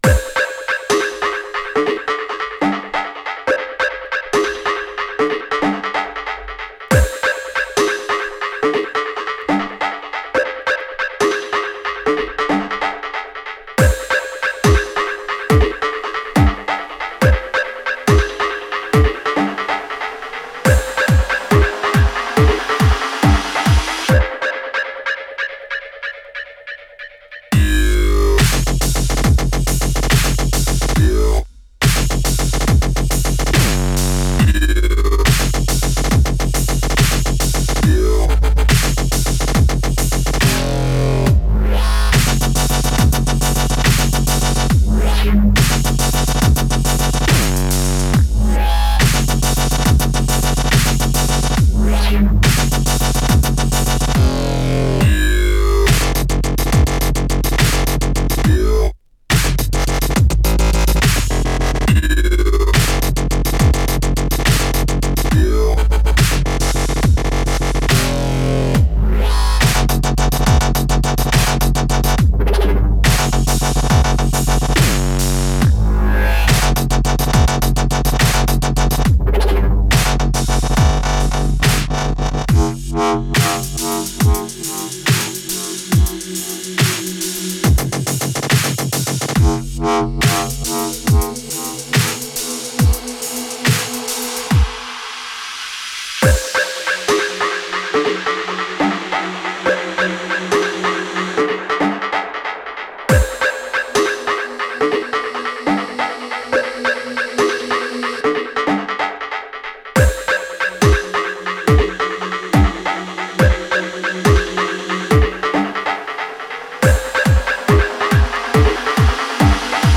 Категория: Dubstep